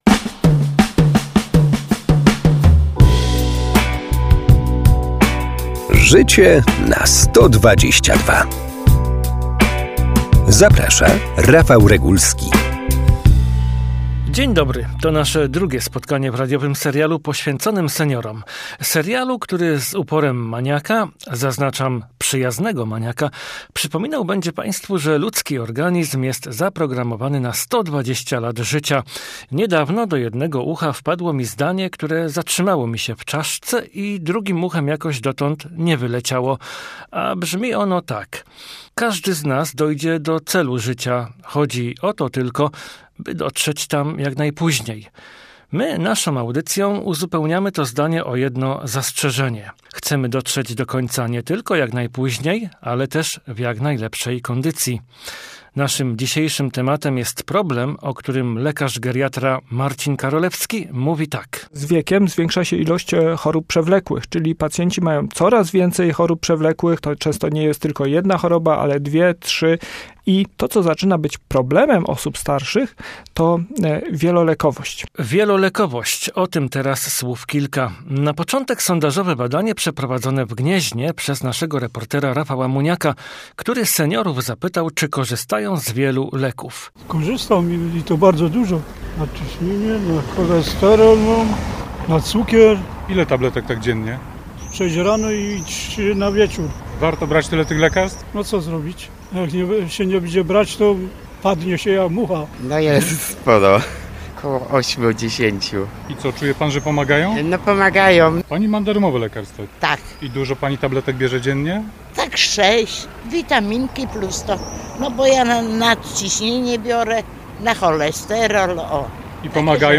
lekarz geriatra
oraz mieszkańcy Gniezna w rozmowie z